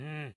记录在Zoom H1上。
Tag: 呼声 anuncio 语音 广播 葡萄牙语 aniversary 该全局-语音 葡萄牙语 雄性 公告